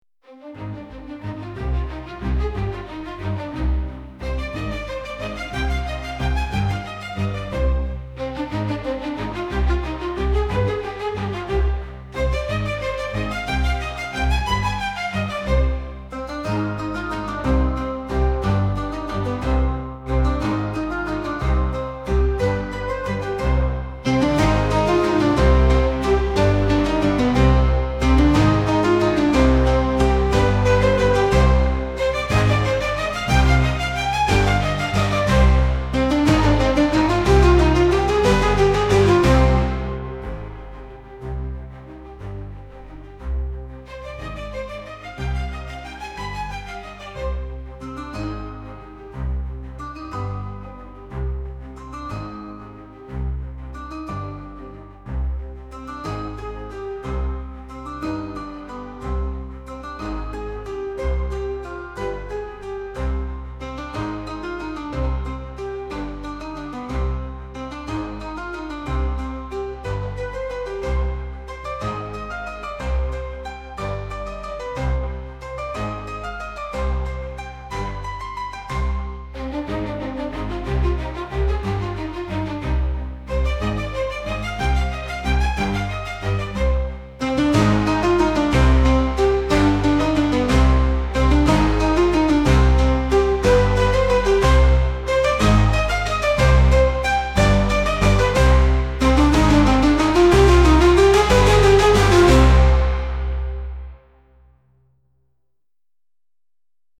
Here is what a moderate (Kp=6) geostorm looks like. Co-starring in this video is Sweet Bird 1, who is teaching Sweet Bird 2 what geostorms look like, and also teaching Sweet Bird 2 diplomatic English, possibly so that it can take part in future co-moderating opportunities.
Location: San Fernando Valley, CA